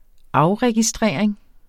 Udtale [ ˈɑwʁεgiˌsdʁεˀɐ̯eŋ ]